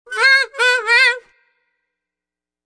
Descarga de Sonidos mp3 Gratis: saxofon 16.